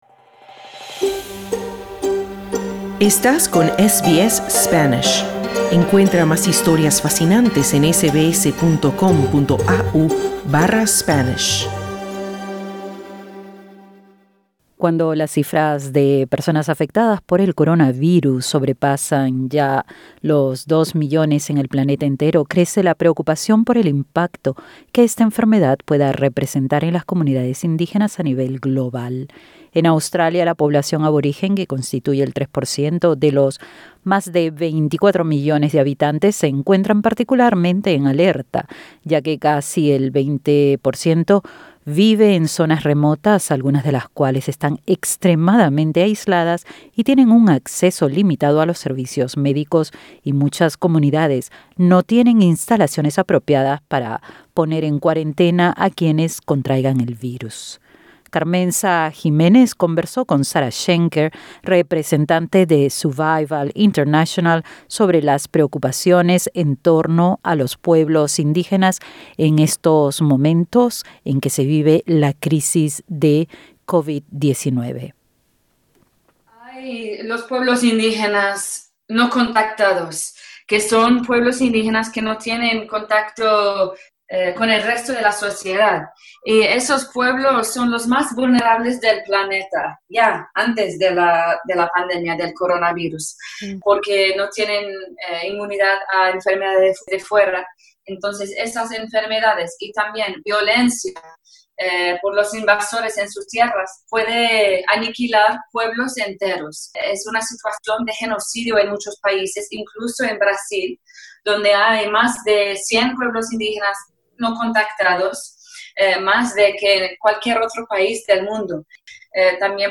alertó en entrevista con SBS Spanish